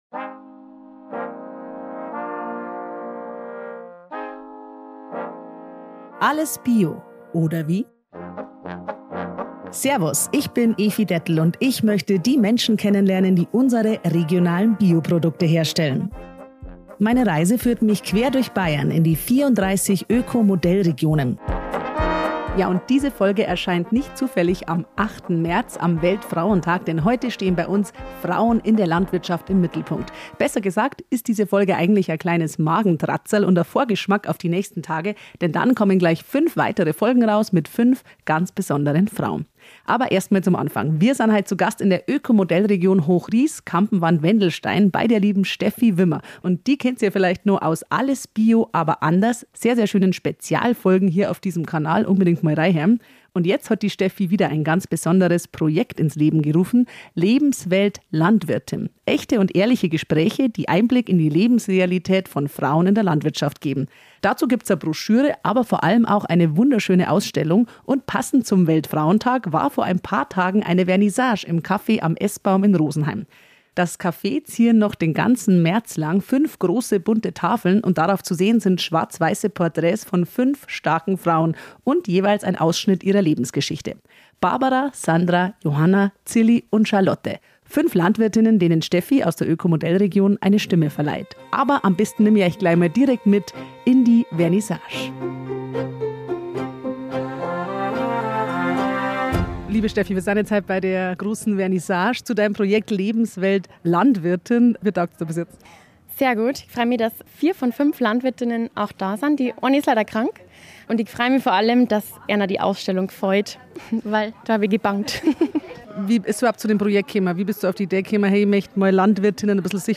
Lebenswelt Landwirtin - Echte & ehrliche Gespräche - Teaser ~ Alles BIO, oder wie?